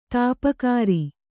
♪ tāpakāri